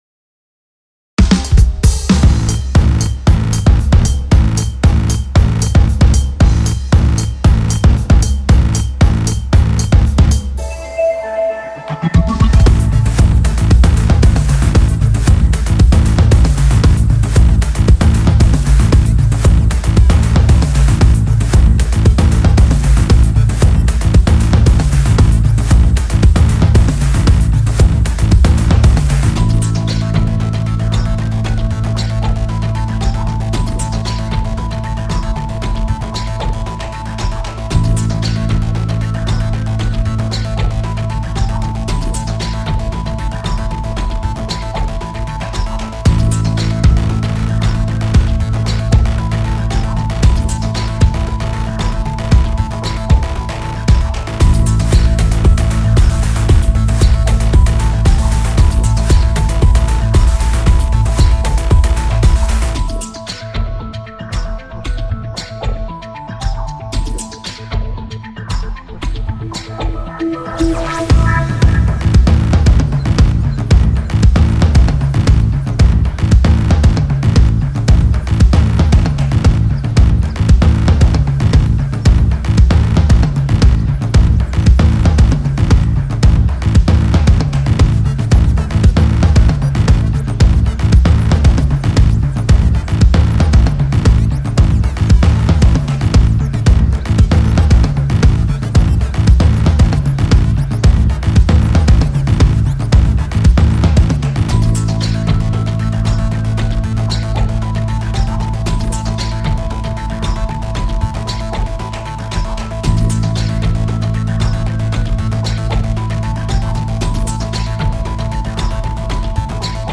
リズム主体のメロディが無い系のBGMです。 緊迫したシーンや、勢いのある場面などに向いているかもしれません。